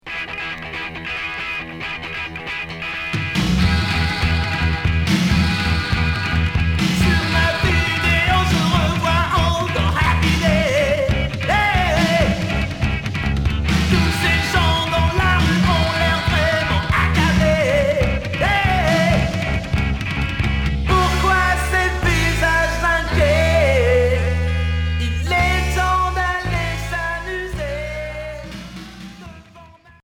Rock Deuxième 45t retour à l'accueil